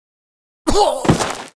SFX男呻吟倒地5音效下载
SFX音效